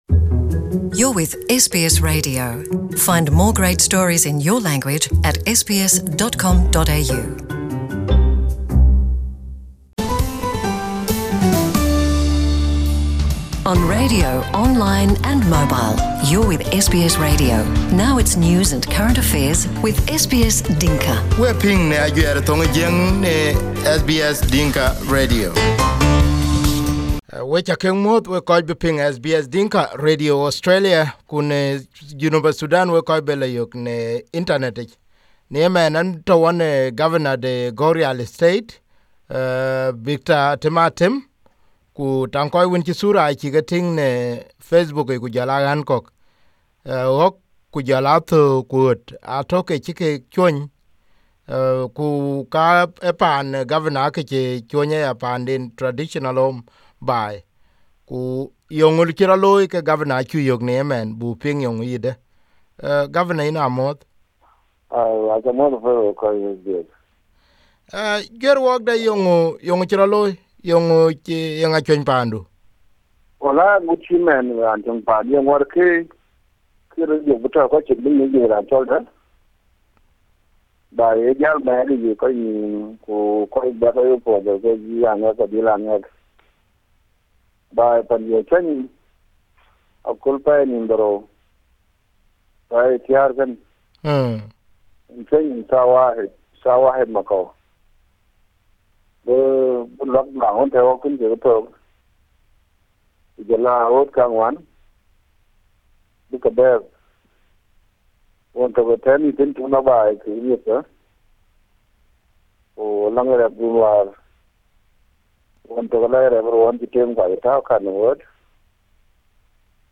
Interview with Governor Atem Atem of Gagrial State after his home was set ablaze killing all his livestock